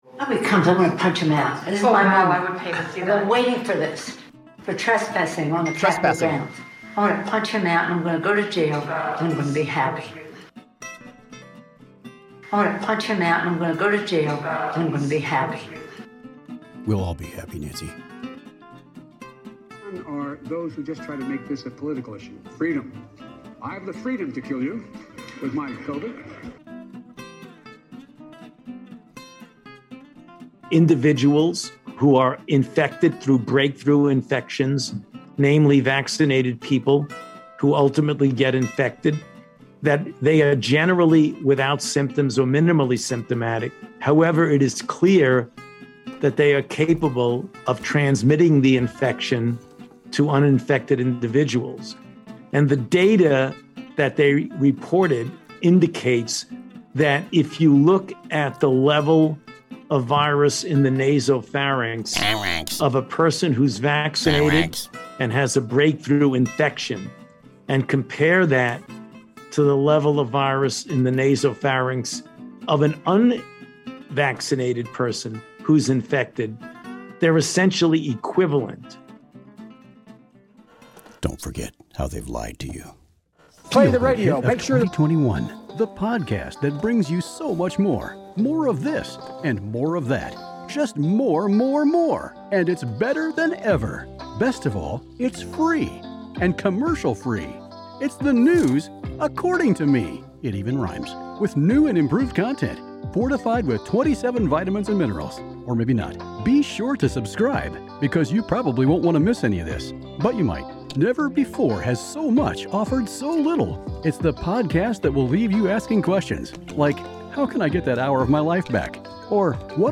Wednesday Live Show! Everything from Ilhan Omar to the straits of Hormuz.